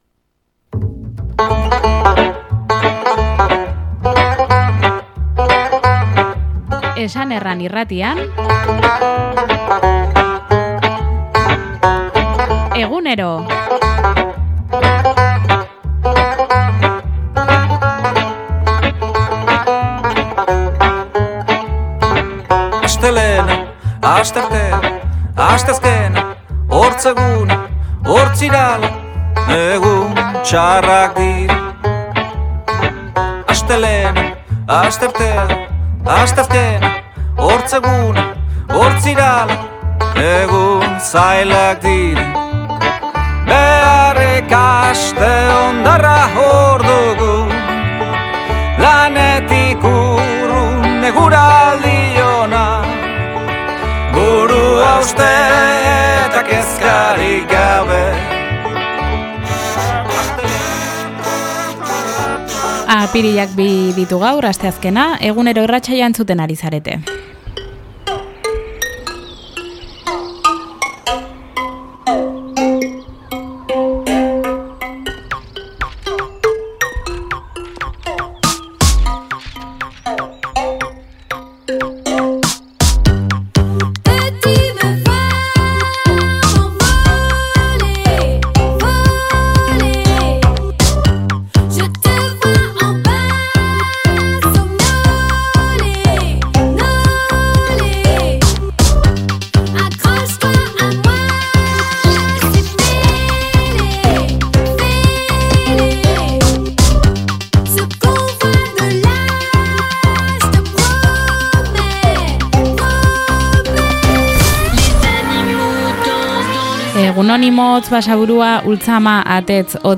eskualdeko magazina